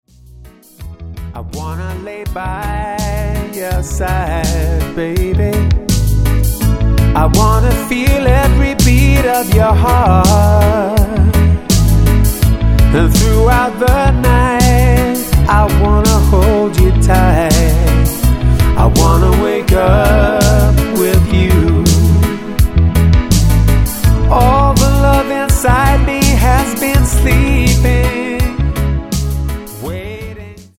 --> MP3 Demo abspielen...
Tonart:E Multifile (kein Sofortdownload.